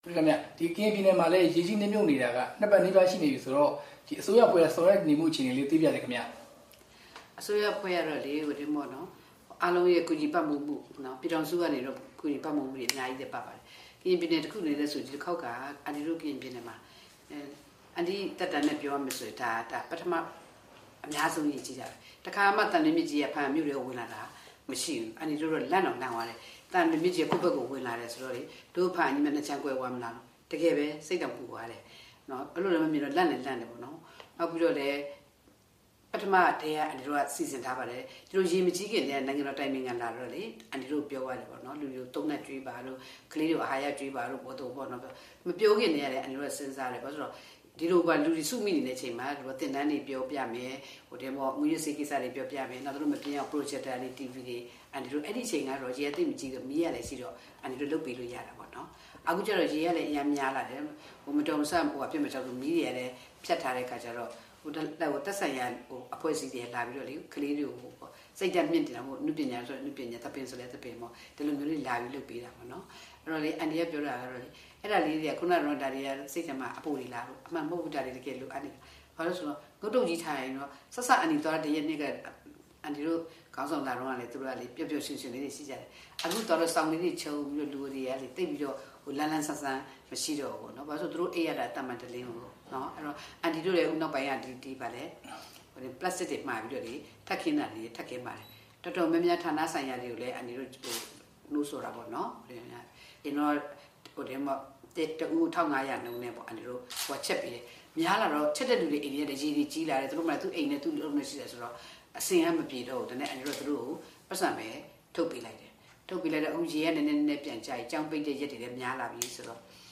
ကရင်ပြည်နယ်ဝန်ကြီးချုပ် ဒေါ်နန်းခင်ထွေးမြင့်နဲ့ မေးမြန်းချက်